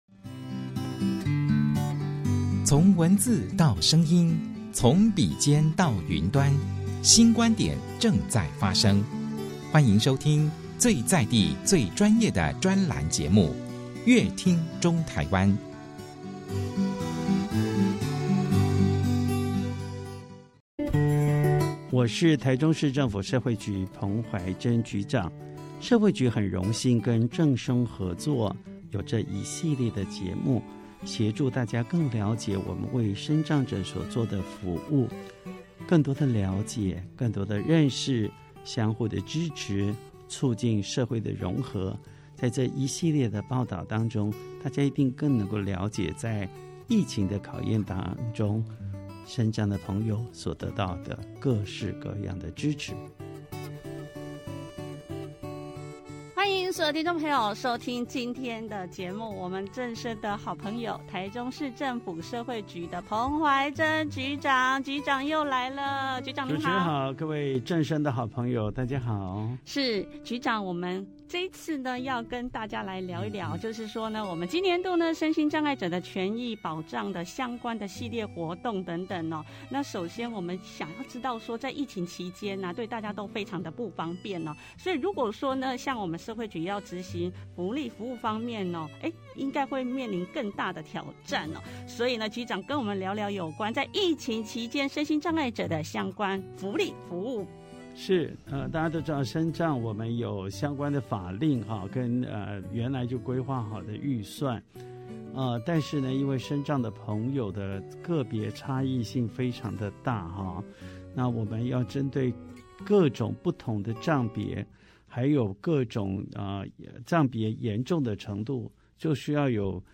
疫情下的身障服務 面對疫情侵襲，對大家的日常生活造成許多不便，想必社會局執行社會福利服務面臨的挑戰更加艱鉅，彭局長在節目中跟大家聊聊有關疫情期間的身心障礙者相關福利服務，希望透過一系列節目，讓聽眾透過聆聽社會局服務過程以及身障朋友和工作人員他們的故事，了解他們、認識他們，透過相互了解，作為社會融合的基礎，請聽眾多多支持。